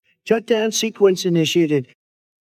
shut-down-sequence.wav